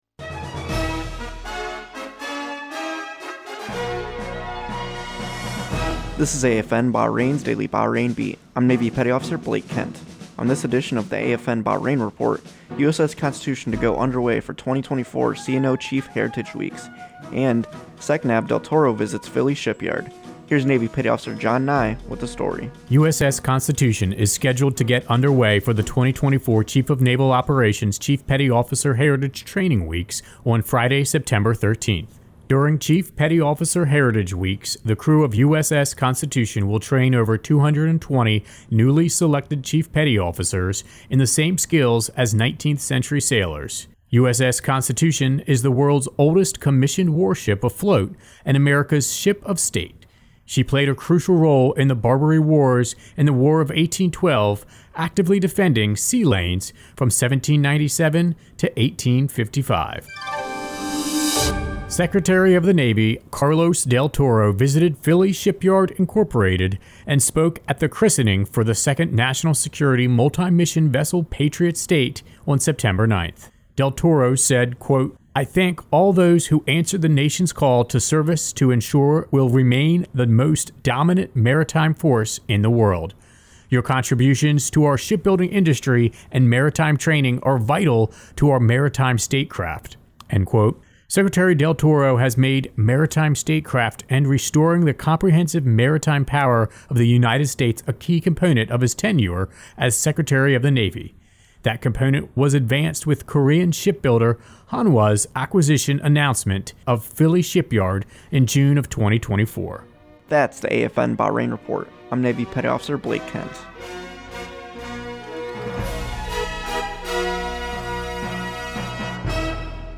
Two-minute newscast covering the USS Constitution to go Underway for 2024 CNO Chief Heritage Weeks and SECNAV Del Toro Visits Philly Shipyard.